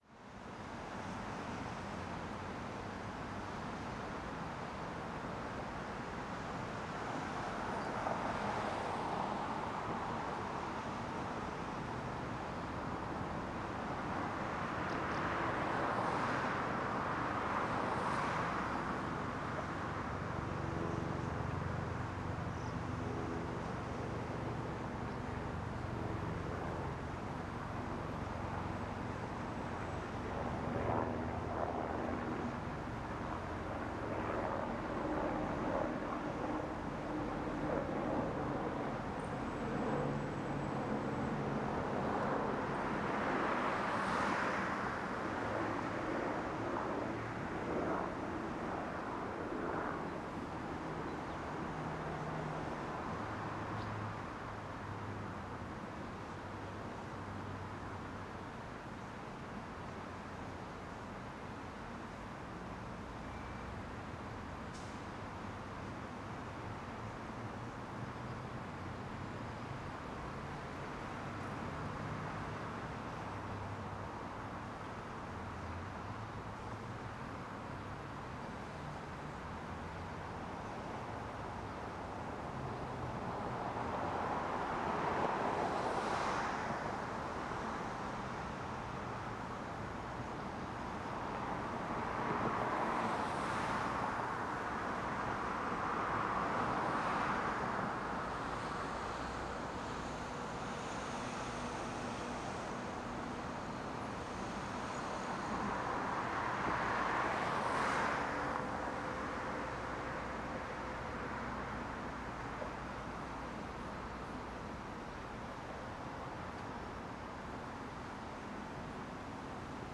City-Underpass_b.ambiX.wav